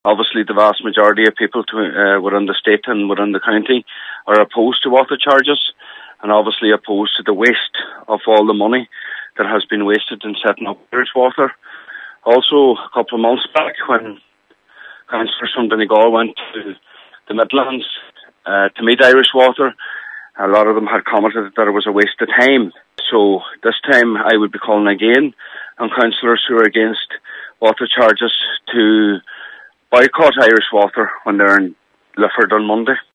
Spokesperson Cllr Michael Cholm Mac Giolla Eaispaig: